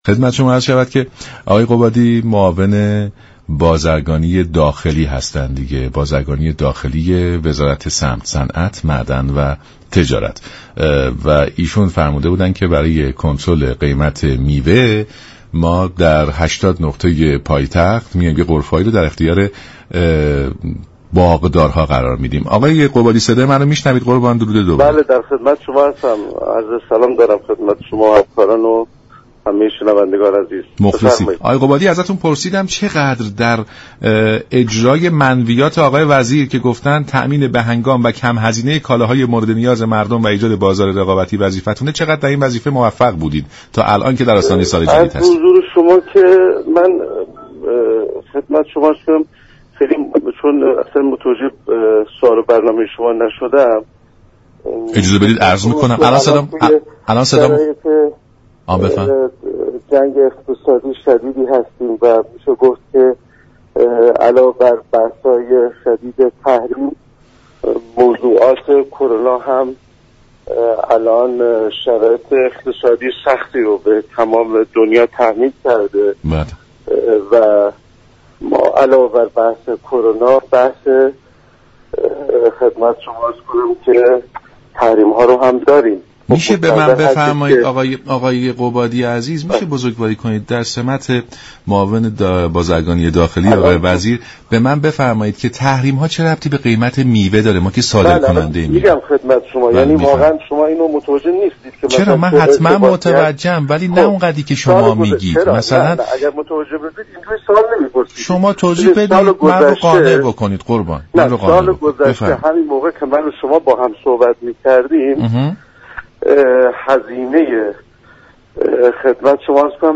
لازم به یادآوری است، گفت و گوی عباس قبادی معاون بازرگانی داخلی وزارت صمت با برنامه سلام صبح بخیر به دلیل عدم پاسخ گویی وی ناتمام ماند.